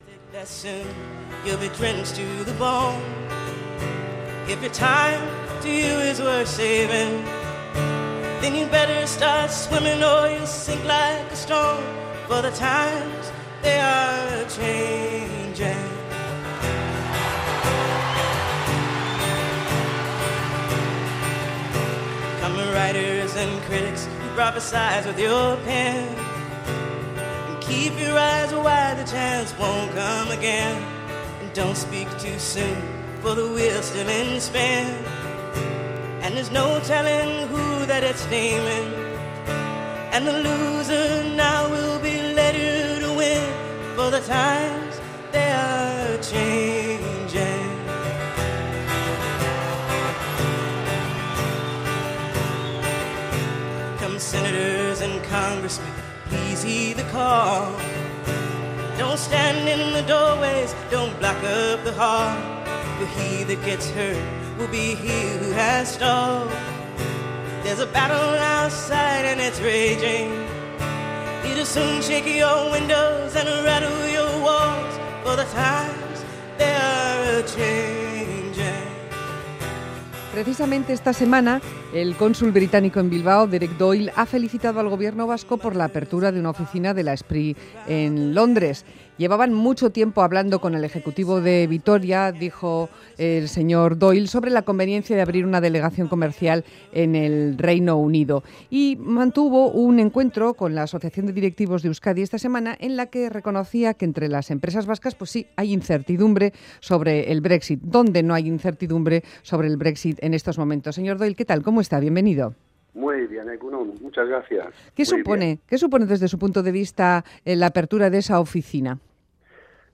Nueva delegación comercial del Gobierno Vasco en Londres: entrevista sobre el futuro de las relaciones comerciales del País Vasco con el Reino Unido.
Radio Euskadi MÁS QUE PALABRAS Nueva delegación comercial del Gobierno Vasco en Londres Última actualización: 09/04/2017 12:03 (UTC+2) Hablamos con Derek Doyle, Consul británico en Bilbao, sobre el futuro de las relaciones comerciales del País Vasco con el Reino Unido.